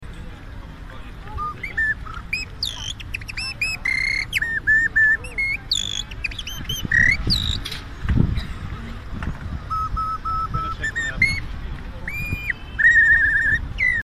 Birdywhistle = world's smallest instrument